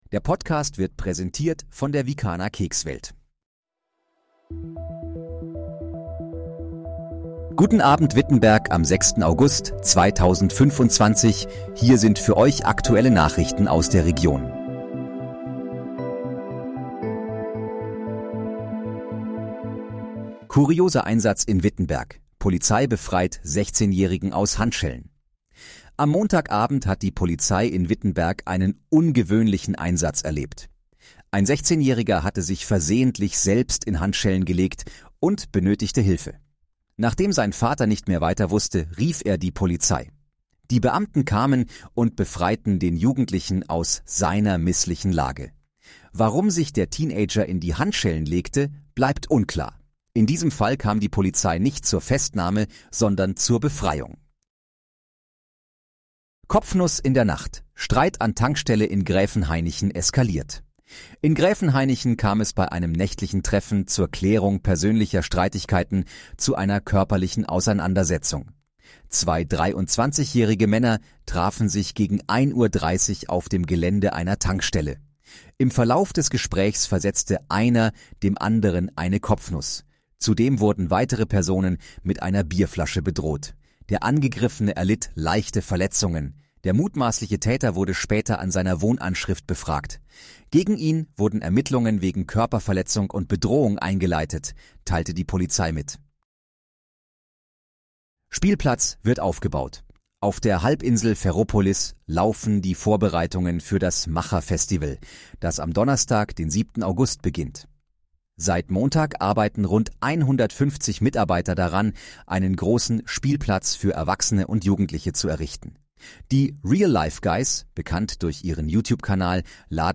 Guten Abend, Wittenberg: Aktuelle Nachrichten vom 06.08.2025, erstellt mit KI-Unterstützung
Nachrichten